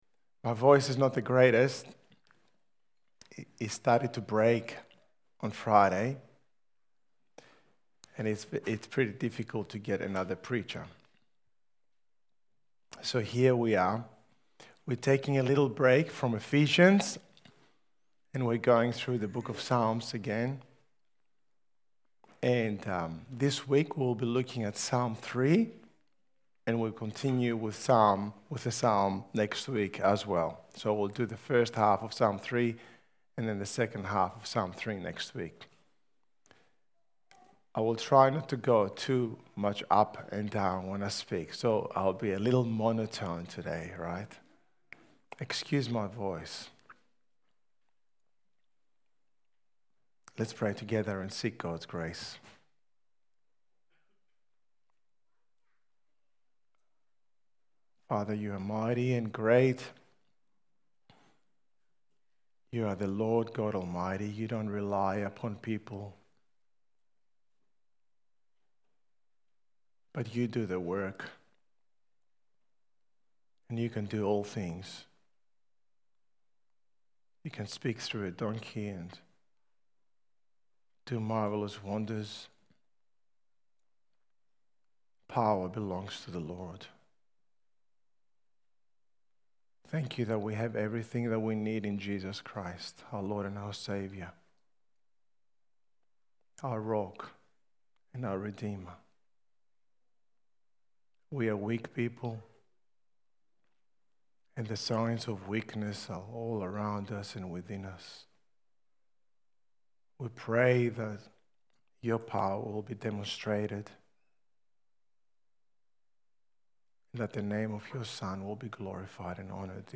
Church-Sermon-261025.mp3